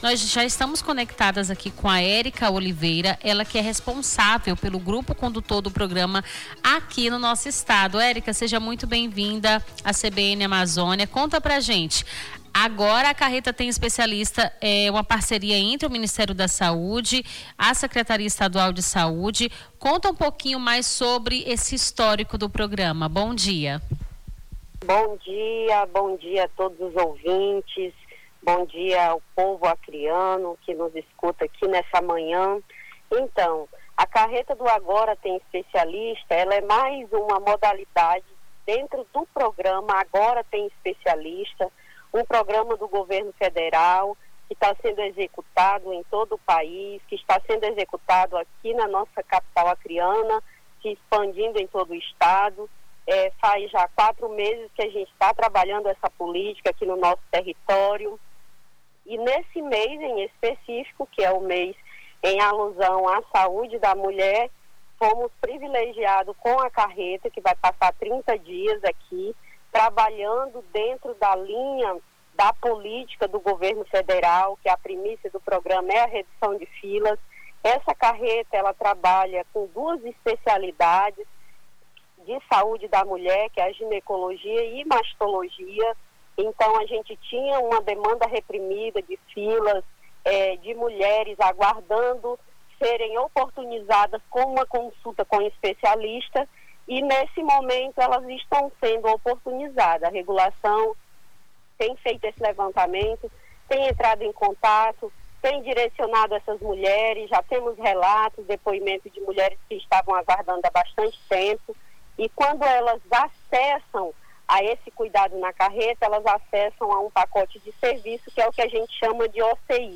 Nome do Artista - CENSURA - ENTREVISTA (CARRETA TEM ESPECIALISTA) 15-10-25.mp3